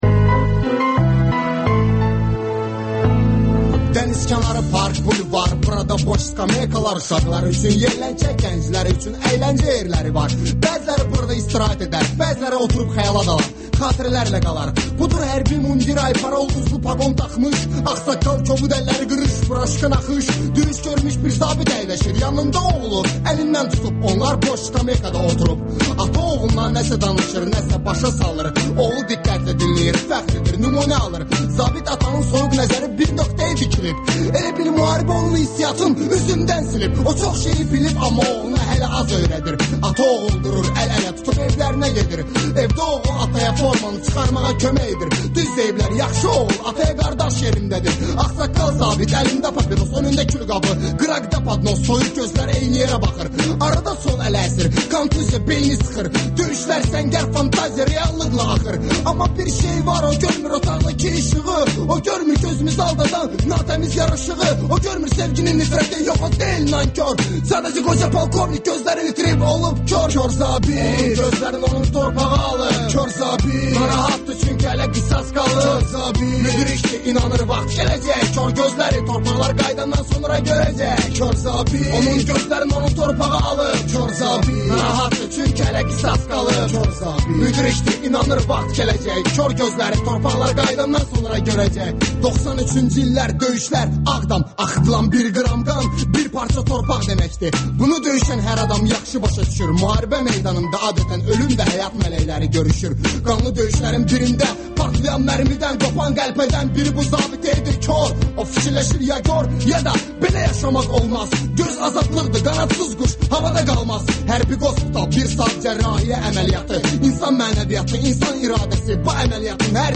Gənclərin musiqi verilişi